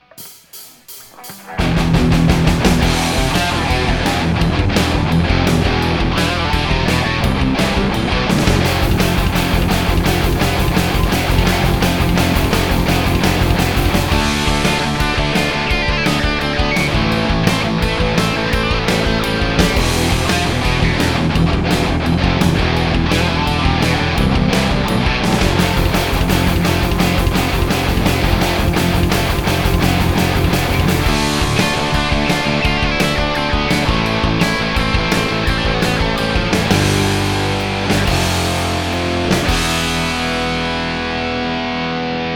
It certainly adds the meat I'm after but there is a bit of flub in there too.
My rhythm track is still 50% left where it was before.